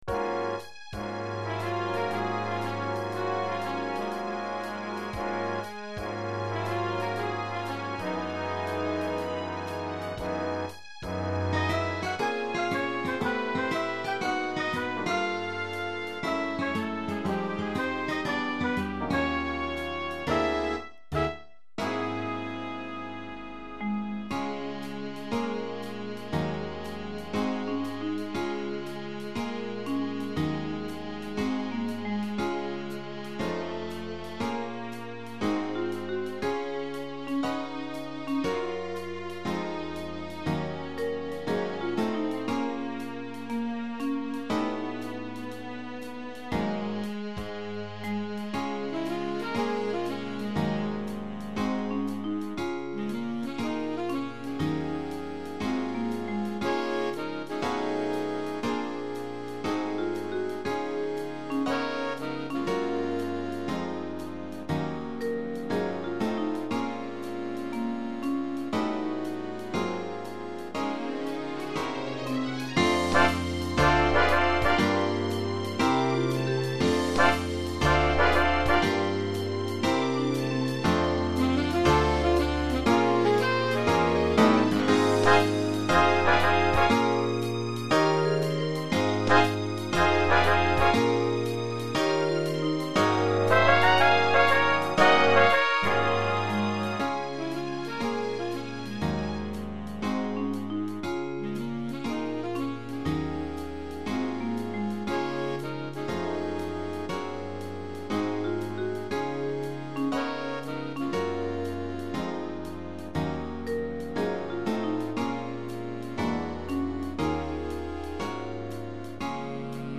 Voix et Big Band